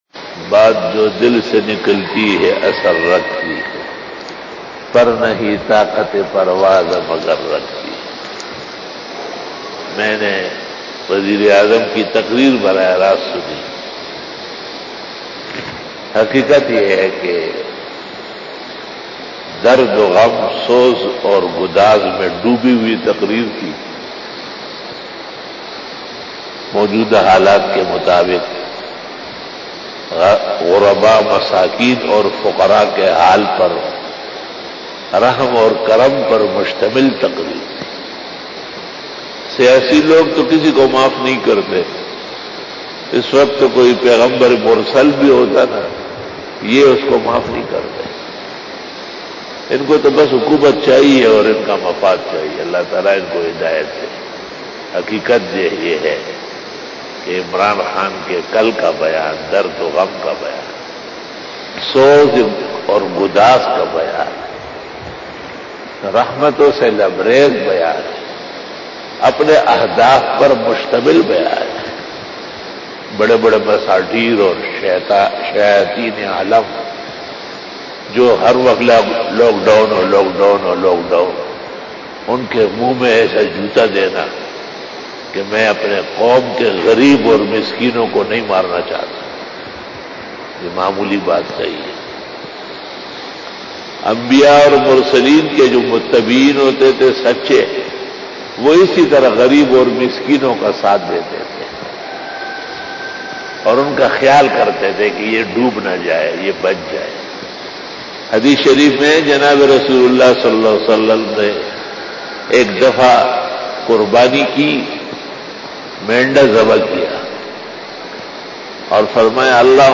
After Fajar Byan
بیان بعد نماز فجر